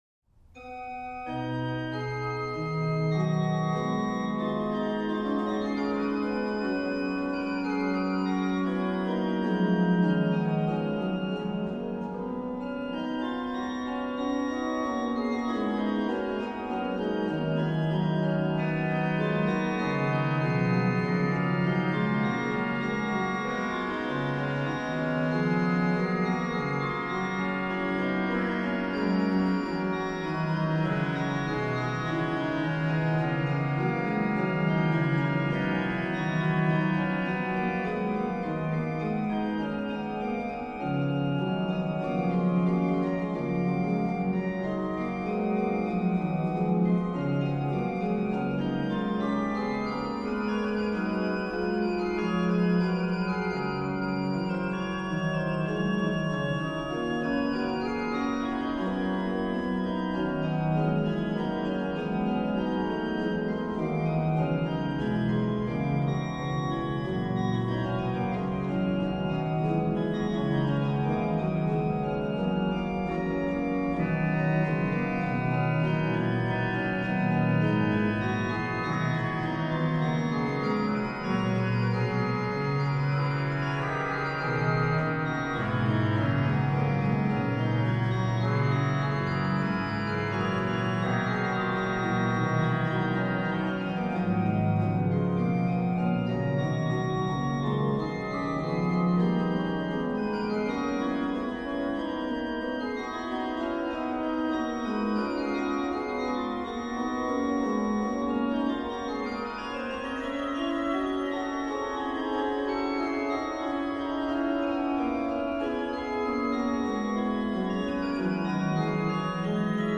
Subtitle   per canones; à 2 claviers et pédale
rh: OW: Ged8, Rfl4, Nas3, 1 3/5
lh: HW: Viol8, Rfl8, Spz4
Ped: Tr8